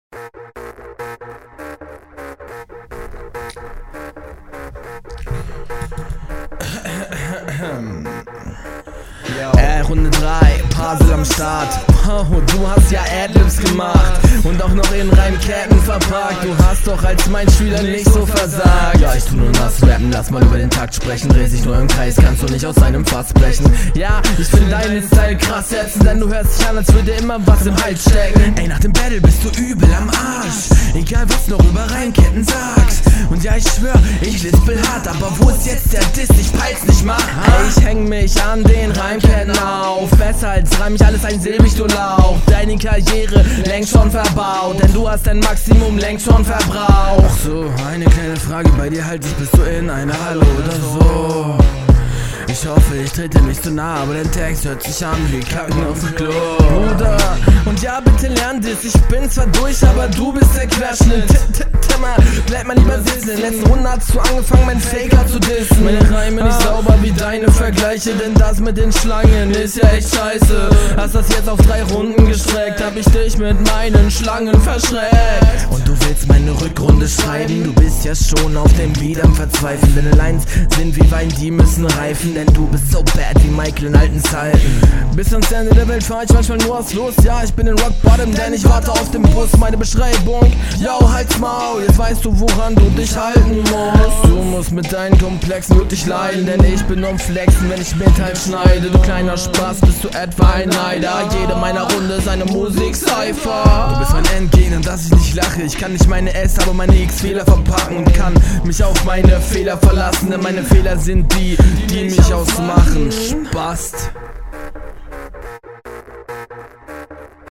Die größte Verständlichkeitsprobleme kommen nicht von deiner Aussprache, sondern von den schlechten Doubles.